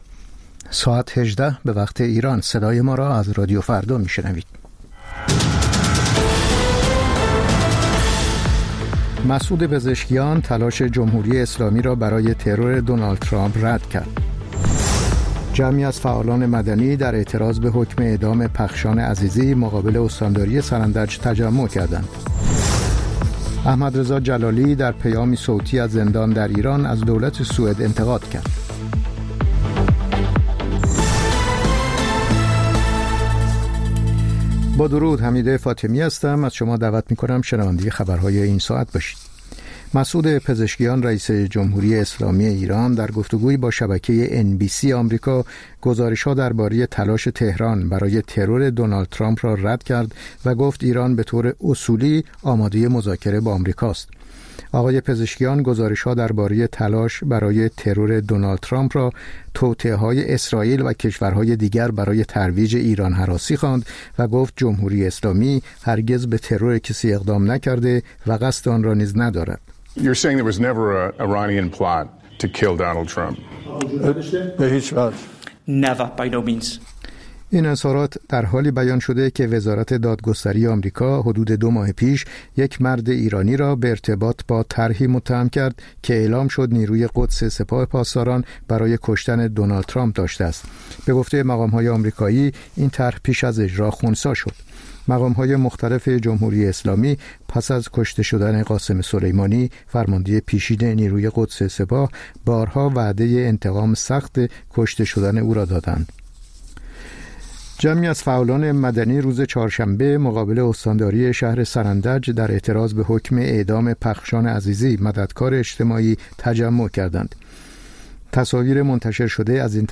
سرخط خبرها ۱۸:۰۰